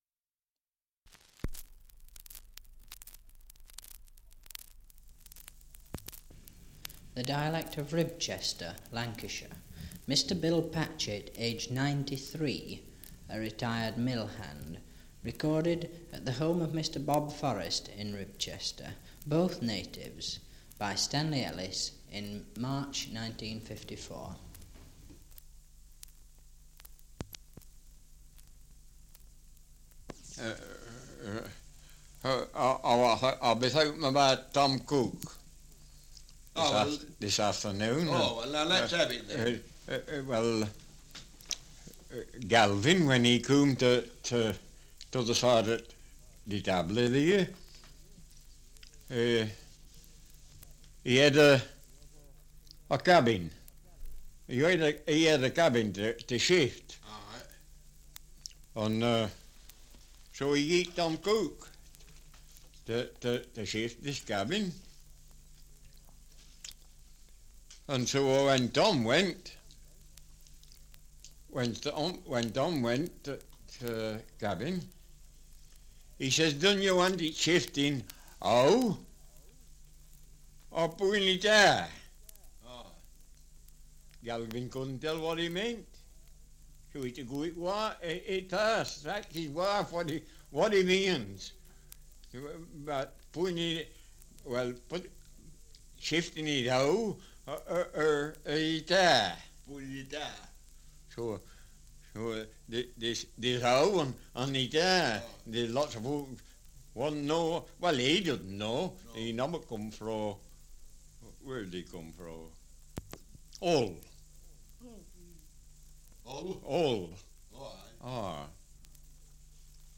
Survey of English Dialects recording in Ribchester, Lancashire
78 r.p.m., cellulose nitrate on aluminium